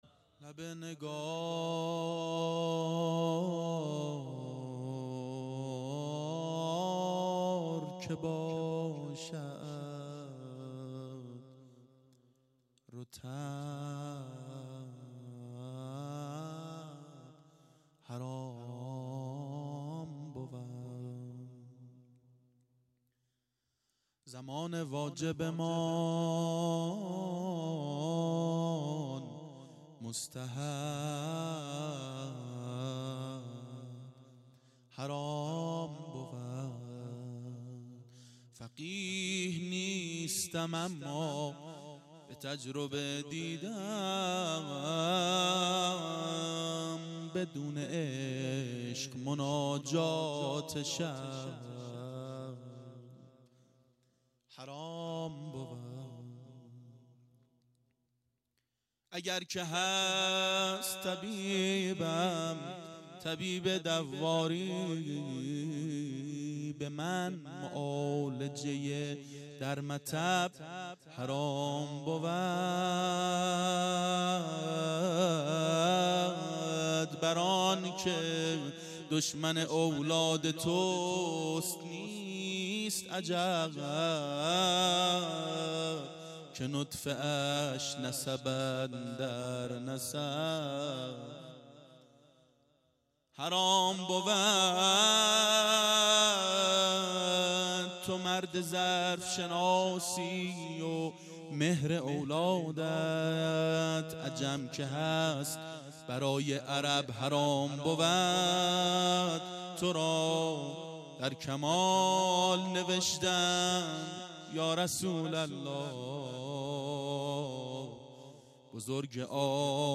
• میلاد پیامبر و امام صادق علیهماالسلام 92 هیأت عاشقان اباالفضل علیه السلام منارجنبان